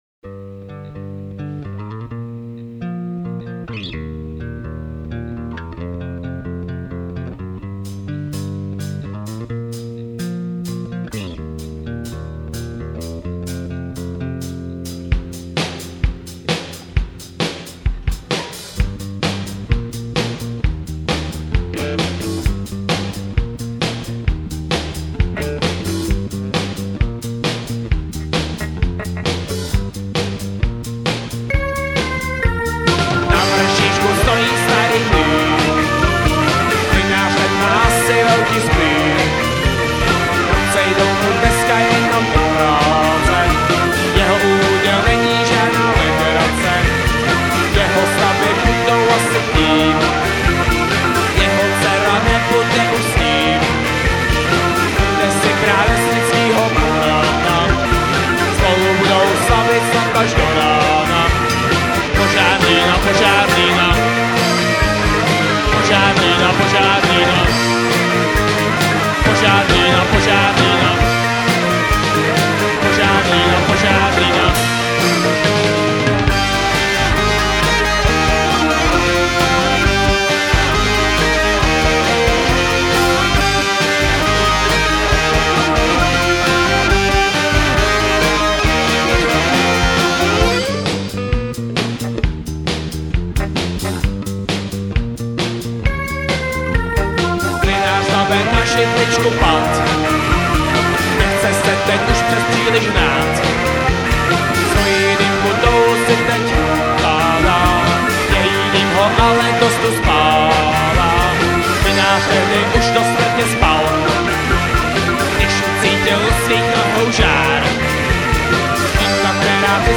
Natočeno živě v dubnu 1999 v Divadle Pod Lampou.